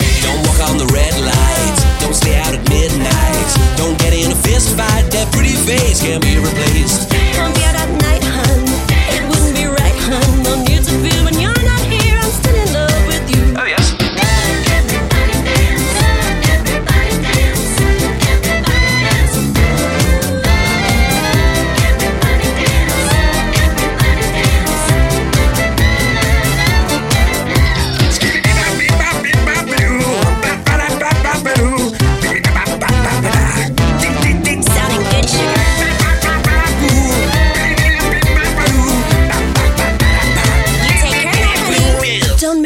Genere: pop, dance